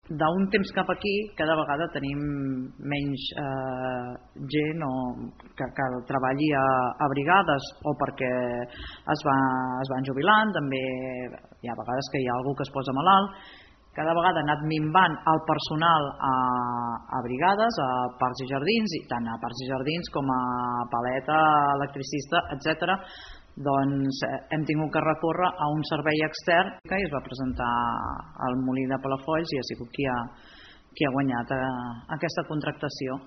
Ho explica l’alcaldessa de Malgrat, Carme Ponsa.